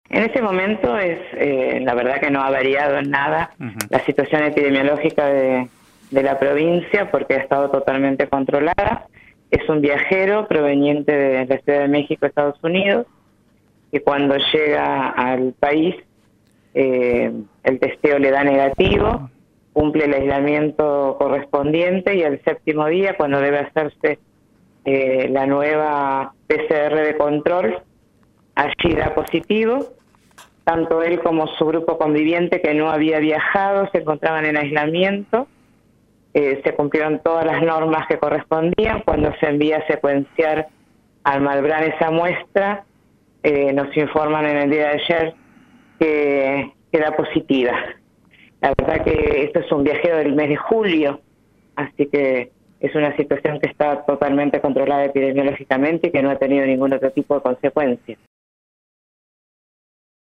En comunicación con FM Bahía Engaño, Miriam Monesterolo, directora del Área Programática de Comodoro Rivadavia, explicó que se trató de un hombre originario de Comodoro, que arribó a la ciudad luego de un viaje por Estados Unidos y México.